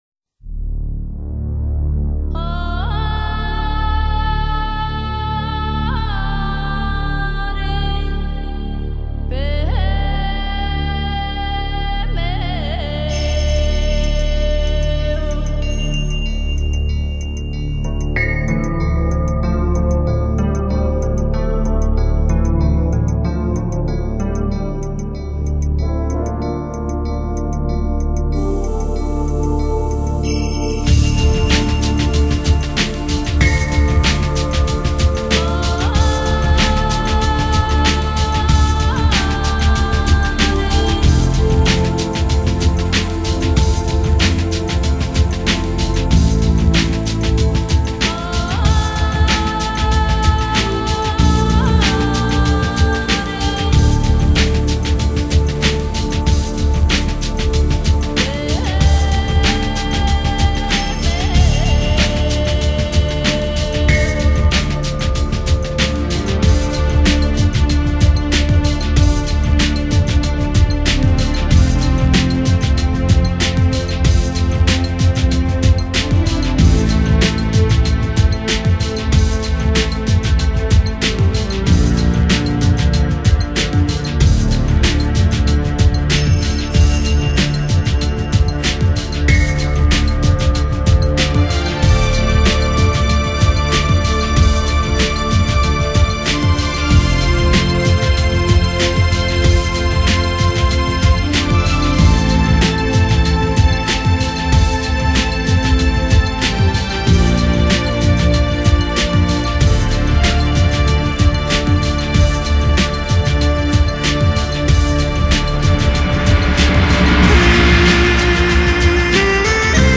国际录音水平24Bit-96Khz天碟品质
演绎荡气回肠的塞外之风
羌笛幽幽，马头琴低唱，霍去病的八百精骑千里驰骋……荡气回肠的塞外之风，感悟远离红尘凡嚣外的另一番心境...